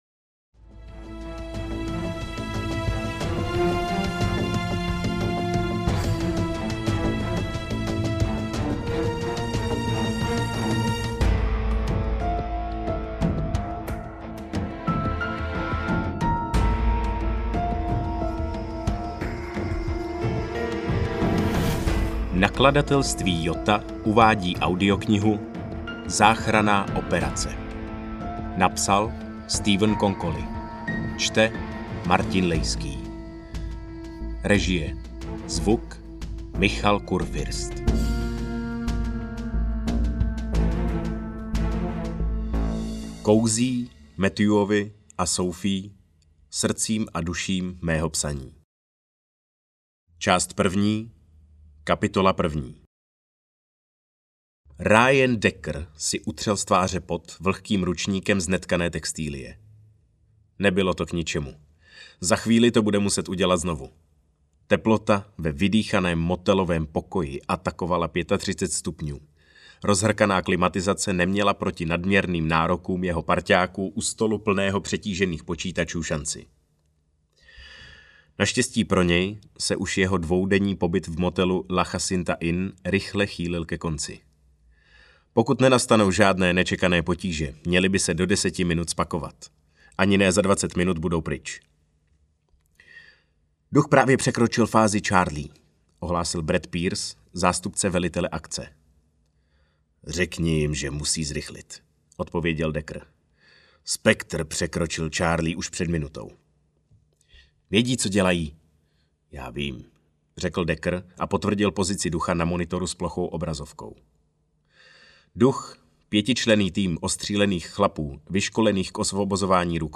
AudioKniha ke stažení, 29 x mp3, délka 12 hod. 23 min., velikost 679,0 MB, česky